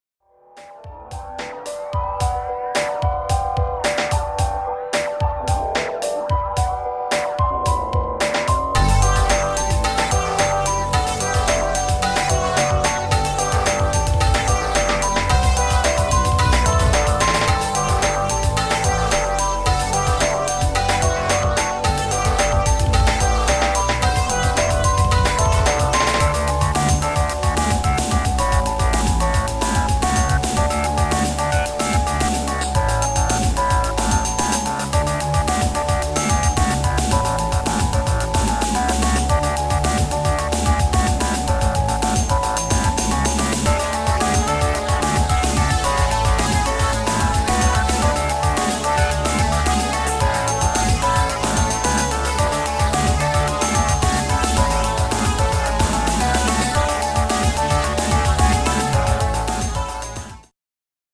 Dreamy, piano driven synth-pop at moderate tempo.